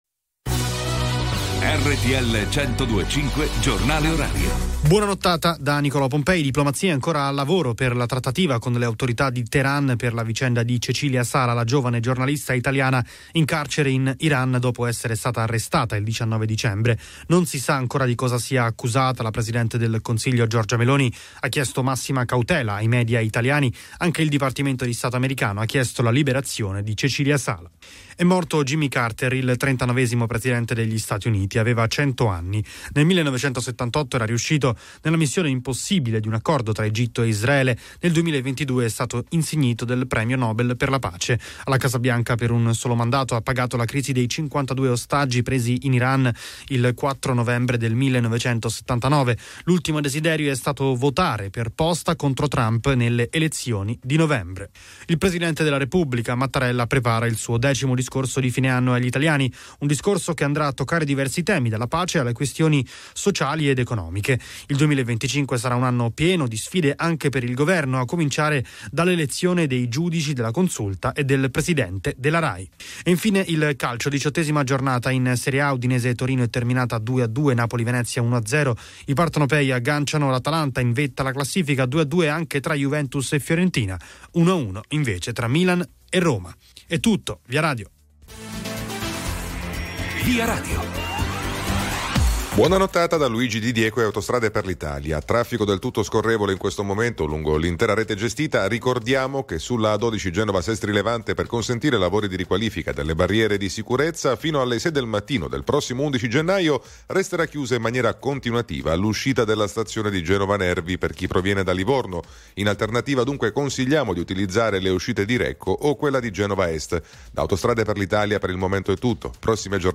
Notizie quotidiane Notizie RTL 102.5 RTL 102.5 Hit Radio s.r.l